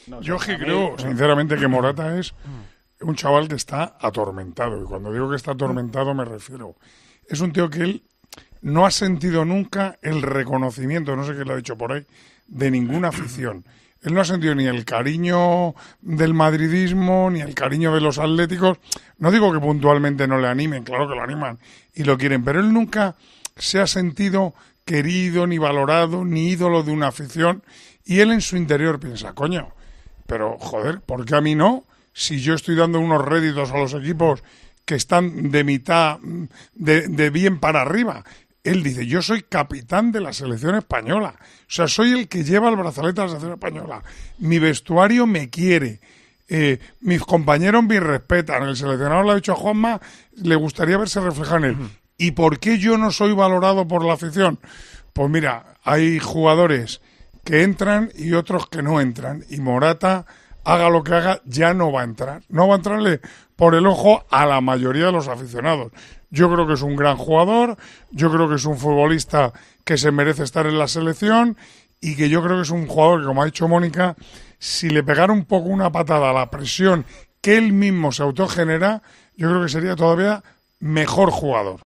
El narrador de Tiempo de Juego analiza la situación del capitán de la selección y le recomienda cómo afrontar lo que queda de Eurocopa.